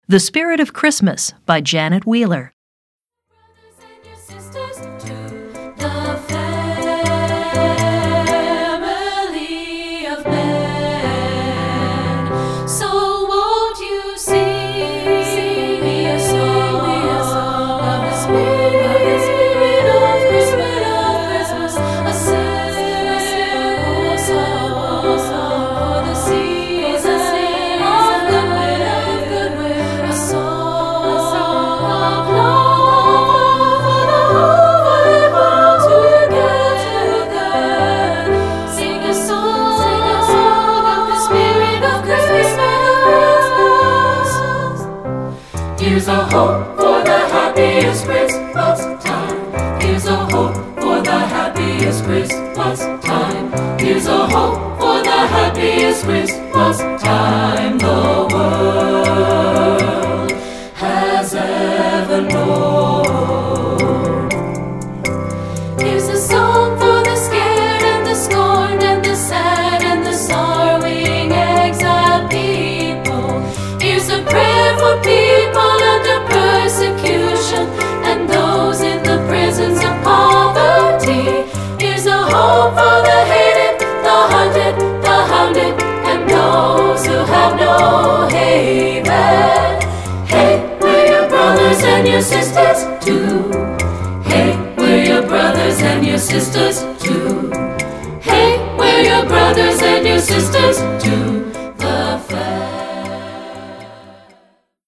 Voicing: SATB and Children's Choir